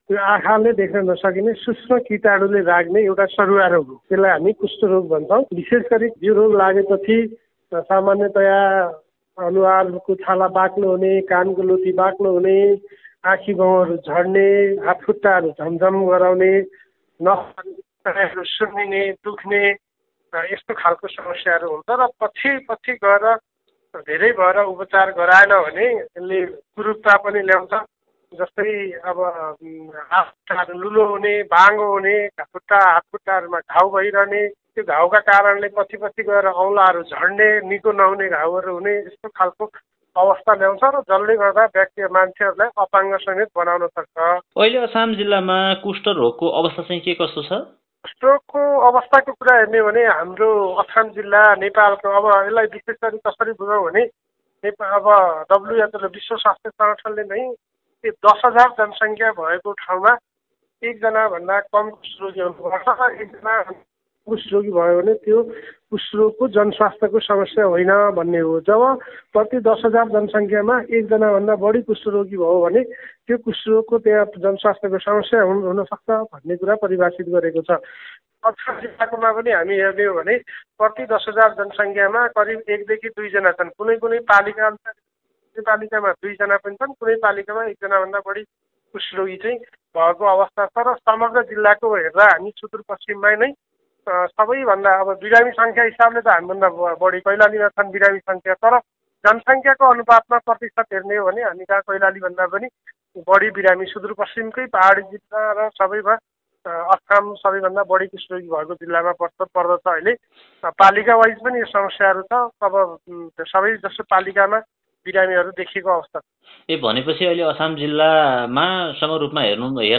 कुराकानी :-